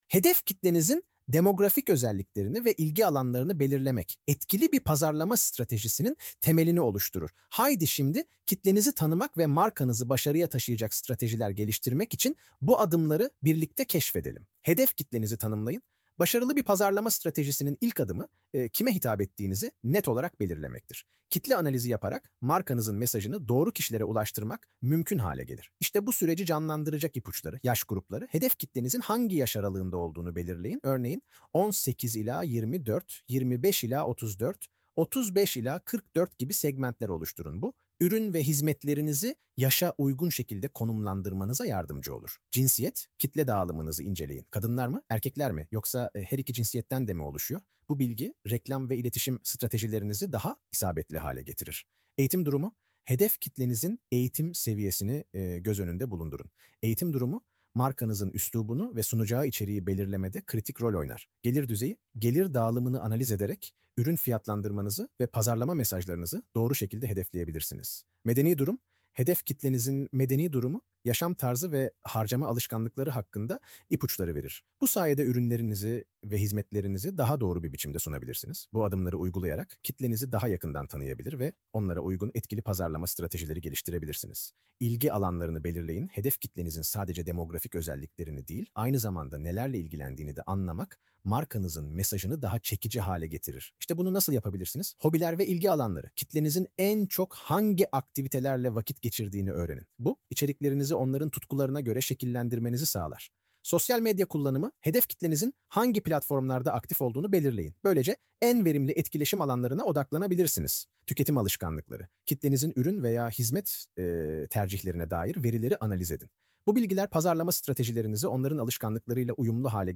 hedef-kitlenizin-demografik-ozelliklerini-ve-ilgi-alanlarini-belirlemek-seslendirme.mp3